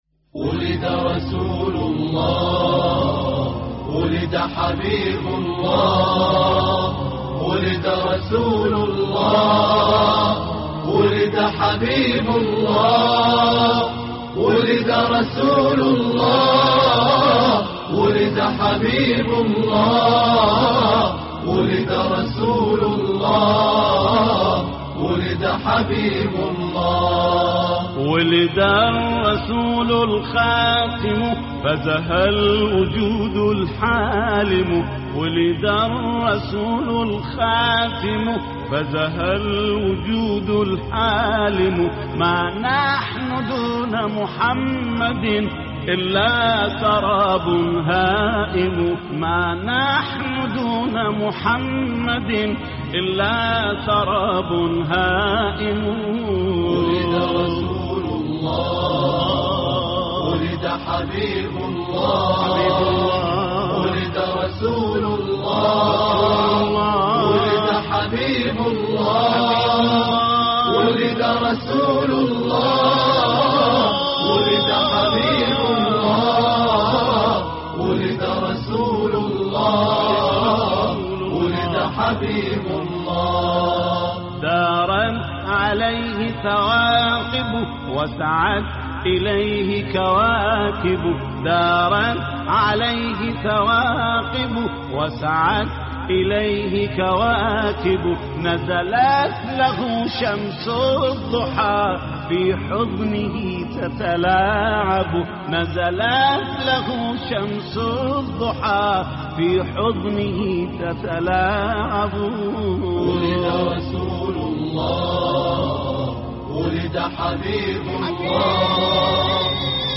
ولد رسول الله (ص) ـ مقام البيات - لحفظ الملف في مجلد خاص اضغط بالزر الأيمن هنا ثم اختر (حفظ الهدف باسم - Save Target As) واختر المكان المناسب